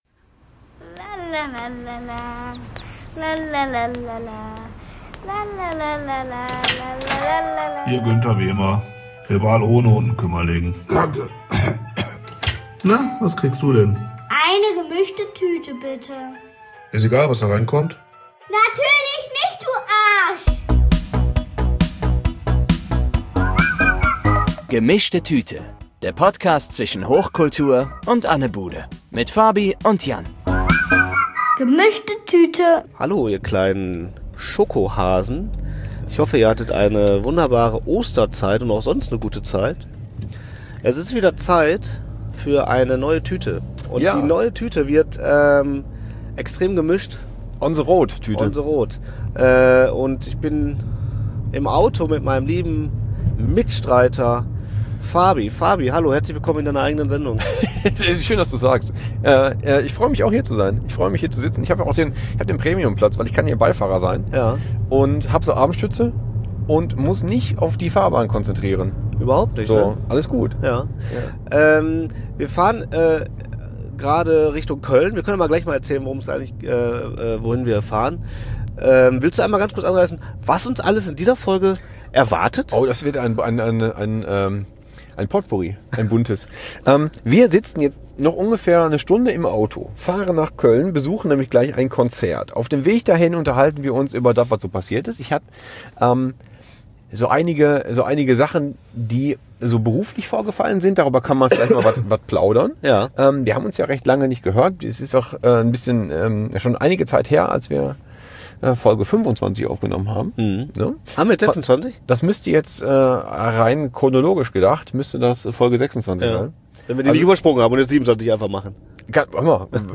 Mit Ausschnitten aus 2 Abenden versuchen wir hier eine neue Folge zu stemmen....
Wir haben Ausschnitte von der BluRay ReleaseParty, vom Film FULL CIRCLE - LAST EXIT ROCK ´N´ROLL, zusammen mit Aufnahmen von unserem Coheed&Cambria-KonzertTrip nach Köln geschnibbelt.